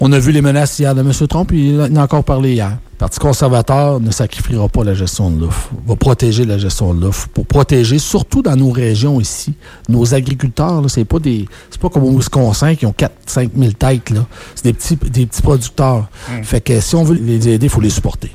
En entrevue à Radio Beauce